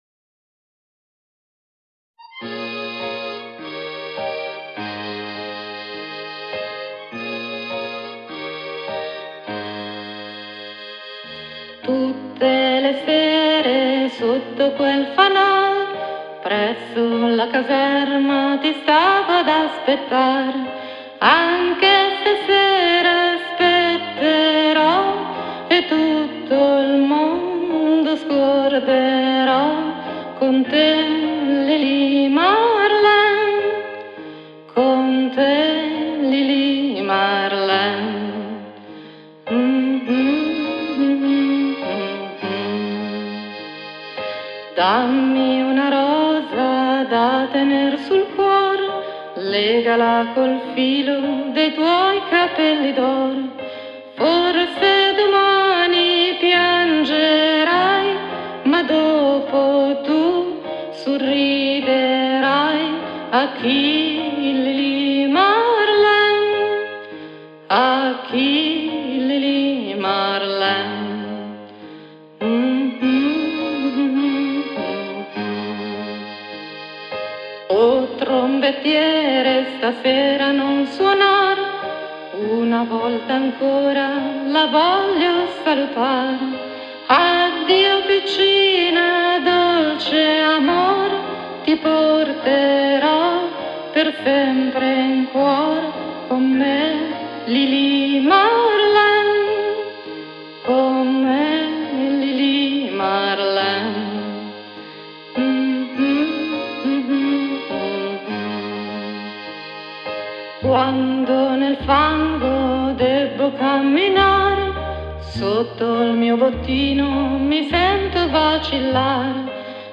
La voce femminile